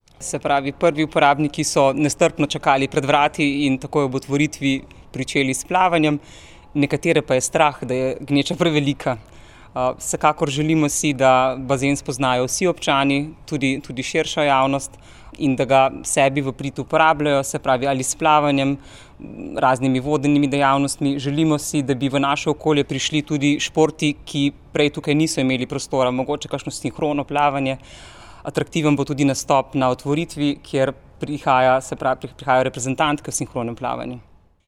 Na novinarski konferenci so o novem bazenu spregovorili